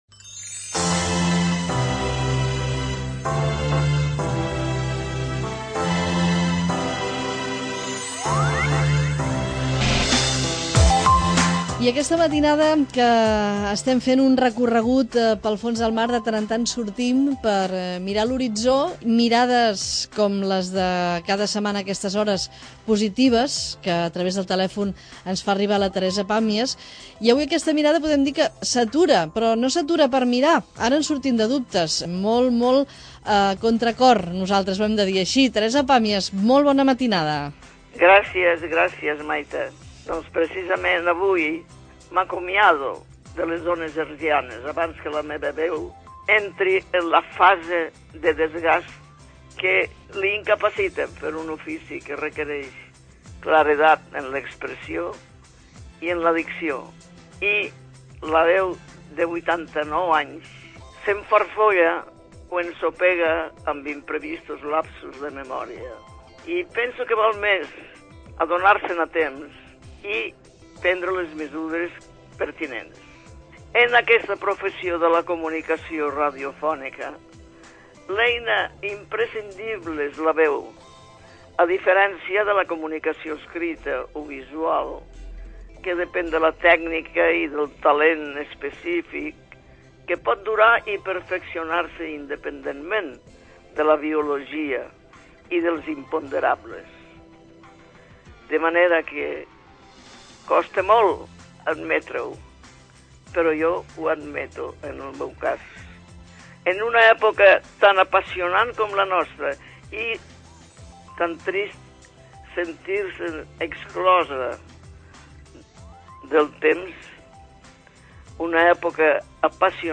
Al programa Geografia humana que es va emetre a Catalunya Ràdio la temporada 2008-2009, Teresa Pàmies hi tenia reservat un espai anomenat "La mirada positiva", que consistia a comentar algun fet que hagués estat notícia durant la setmana i que pretenia "...recuperar les ganes de creure que no tot està perdut".
El 16 de març de 2009, a l'edat de 89 anys i per decisió pròpia, va fer la seva darrera intervenció: